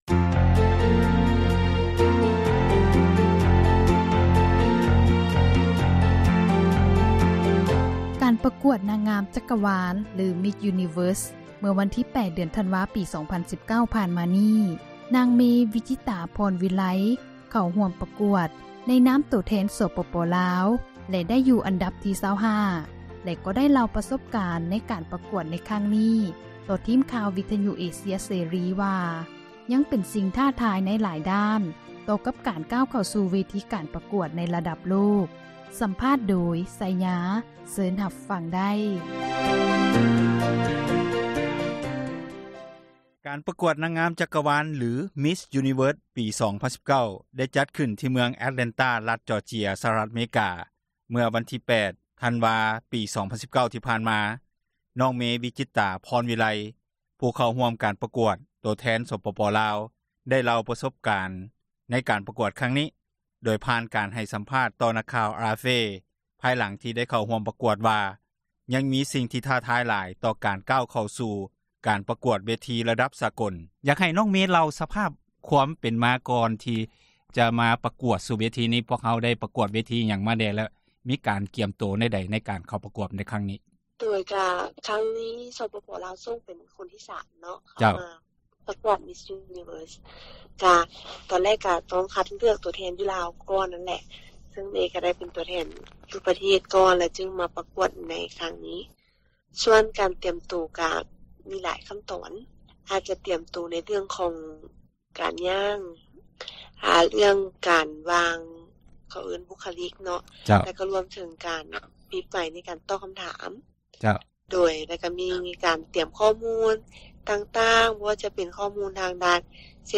ສັມພາດຜູ້ເຂົ້າປະກວດ ນາງງາມ ຈັກວານ 2019